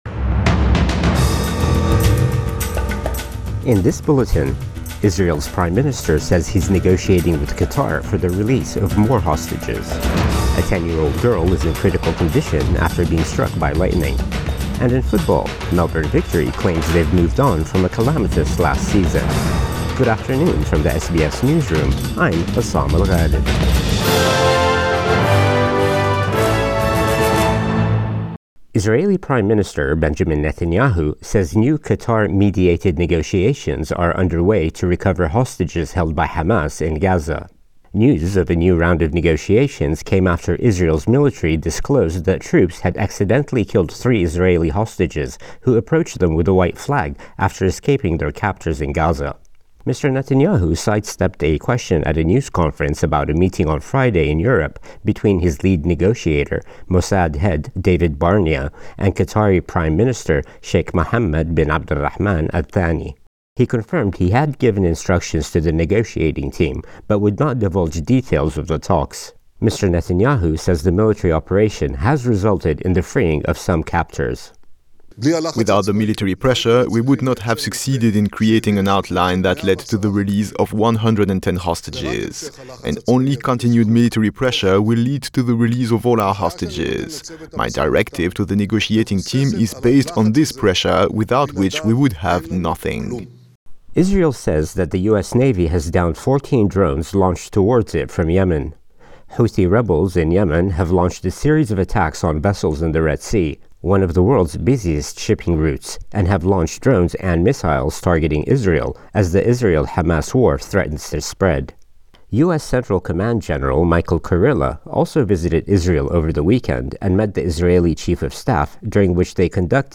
Midday News Bulletin 17 December 2023